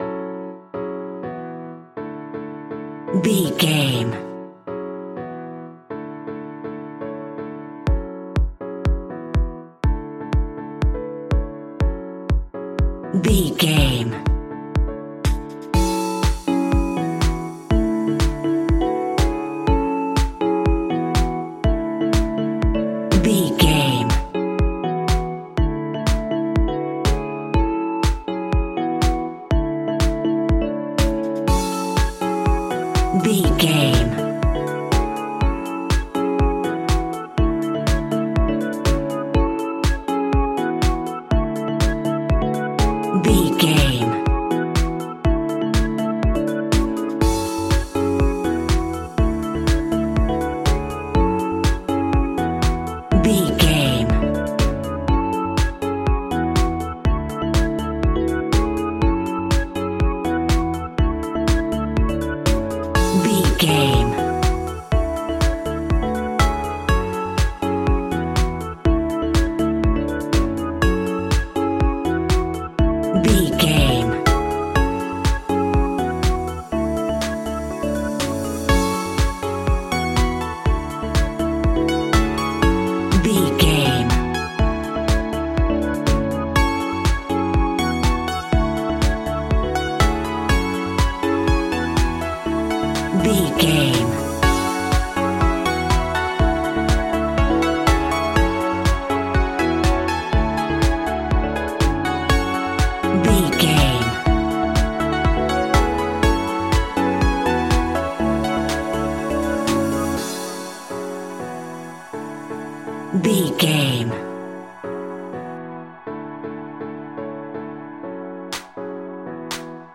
Aeolian/Minor
F#
groovy
happy
piano
drums
drum machine
synthesiser
house
Funk
electro house
instrumentals
synth leads
synth bass